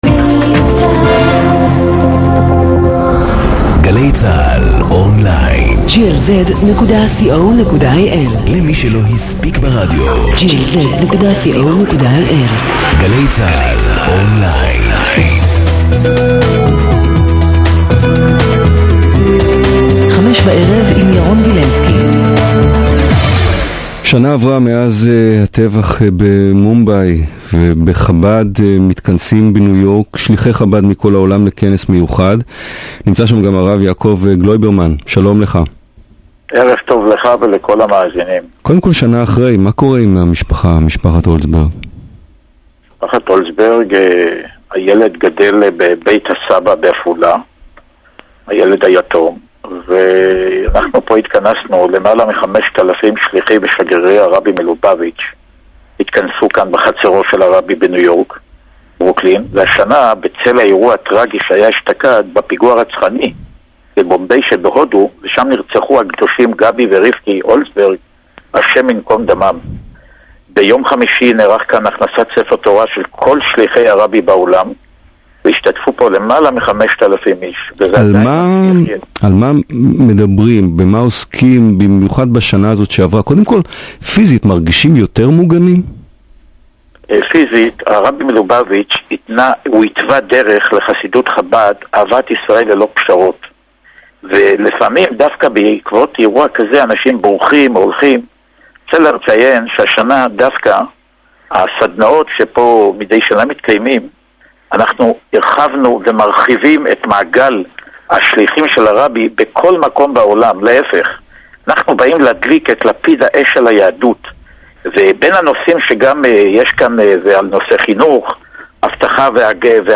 התראיין לתוכנית "חמש-בערב" עם ירון ולינסקי המשודרת בגלי צה"ל